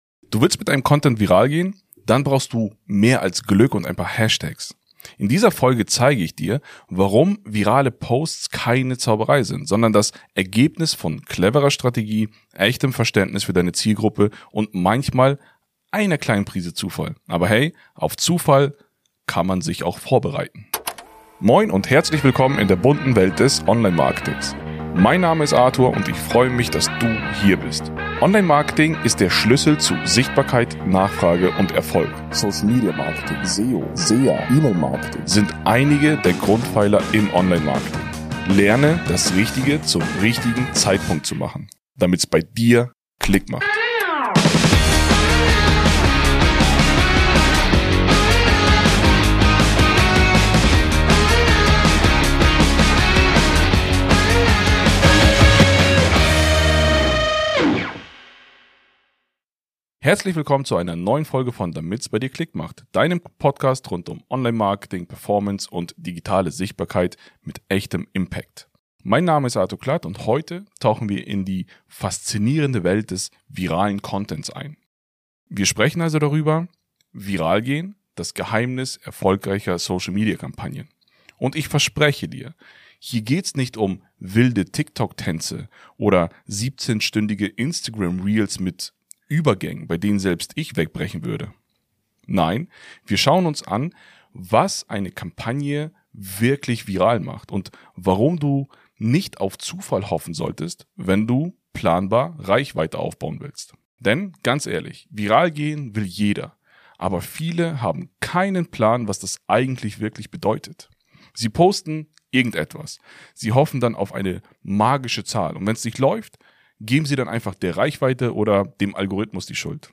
Was steckt wirklich hinter viralen Posts? In dieser Solo-Folge zeige ich dir, wie erfolgreiche Social-Media-Kampagnen entstehen – mit Psychologie, Strategie und einem Schuss Überraschung. Plus: Die wahre Geschichte hinter einem viralen Hit, der so gar nicht geplant war.